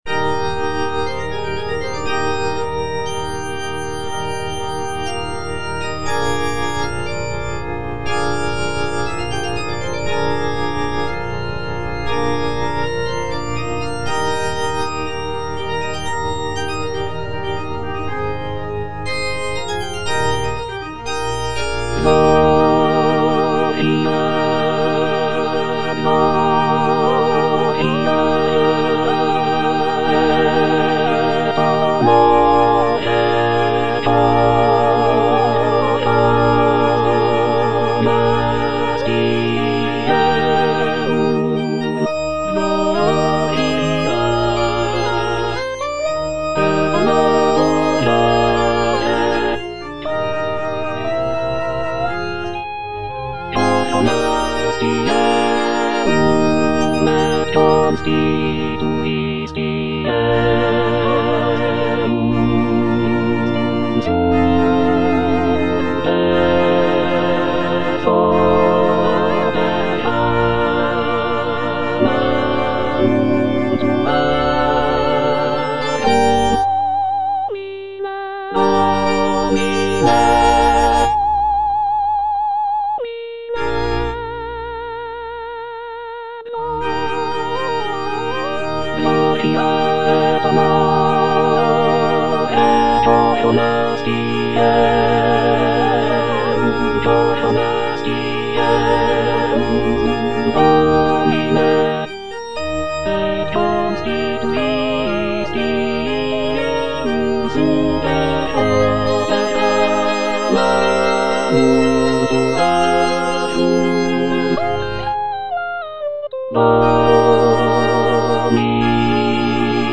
C.M. VON WEBER - MISSA SANCTA NO.1 Offertorium (All voices) Ads stop: auto-stop
"Missa sancta no. 1" by Carl Maria von Weber is a sacred choral work composed in 1818.
The work features a grand and powerful sound, with rich harmonies and expressive melodies.